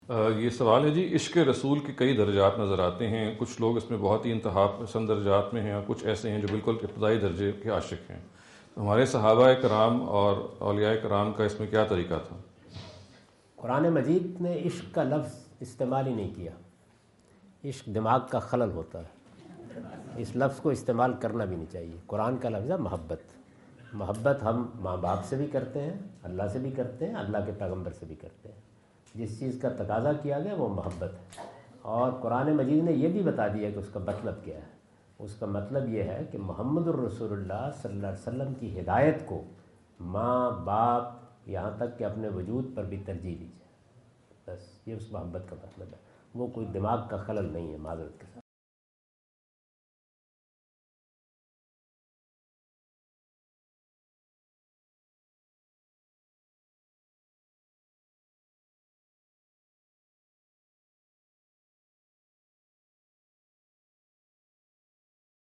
Category: English Subtitled / Questions_Answers /
جاوید احمد غامدی اپنے دورہ امریکہ 2017 کے دوران کورونا (لاس اینجلس) میں "عشقِ رسول ﷺ کے درجات" سے متعلق ایک سوال کا جواب دے رہے ہیں۔